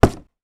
Punching Box Powerful A.wav